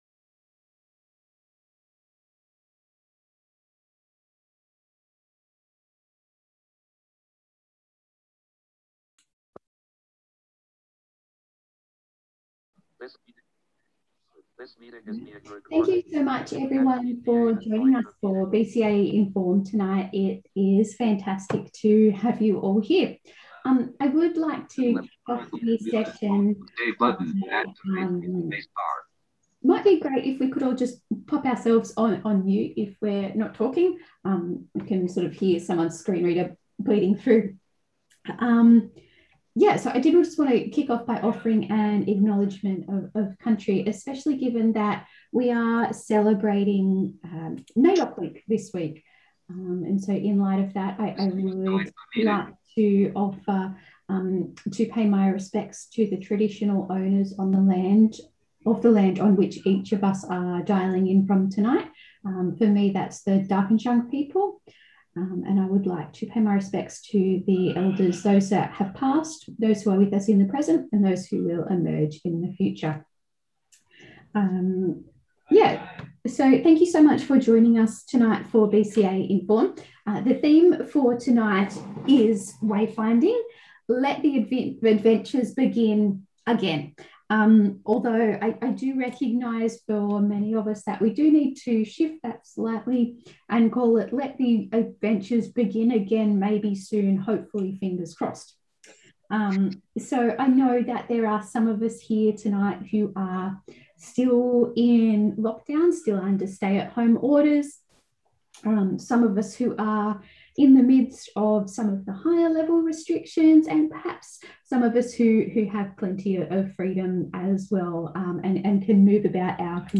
In May of 2021, at BCA Connect, we began a discussion around wayfinding and the impact of COVID-19, technology, and BCA’s work in this area.